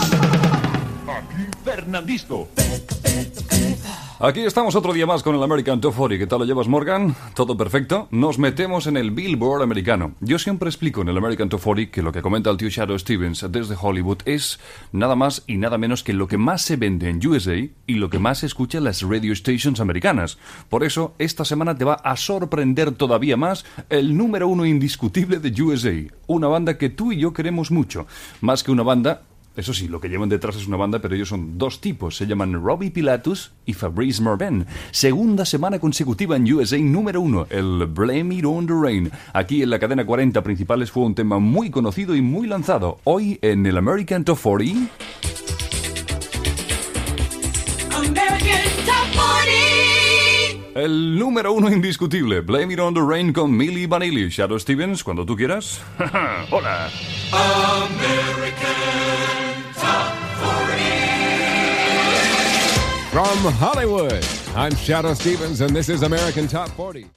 Indicatiu del locutor, presentació inicial i comentari del número 1 al Billboard dels Estats Units i entrada de Shadoe Stevens
Musical